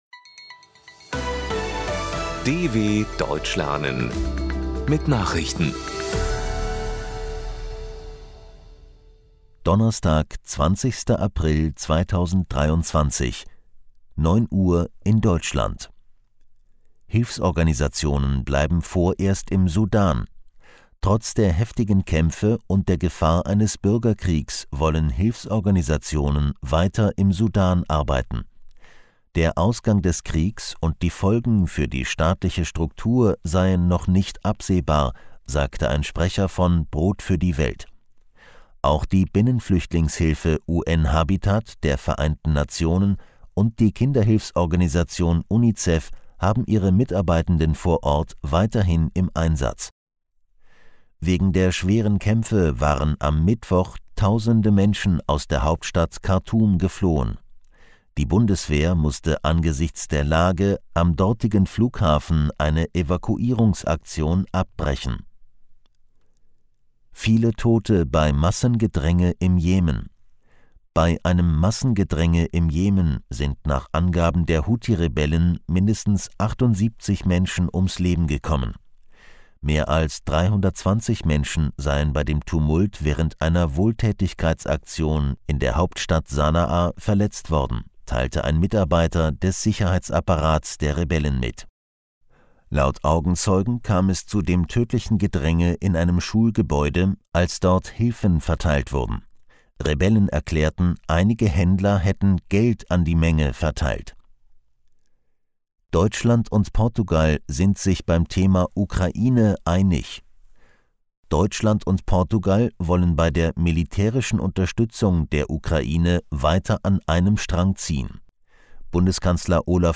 20.04.2023 – Langsam Gesprochene Nachrichten
Trainiere dein Hörverstehen mit den Nachrichten der Deutschen Welle von Donnerstag – als Text und als verständlich gesprochene Audio-Datei.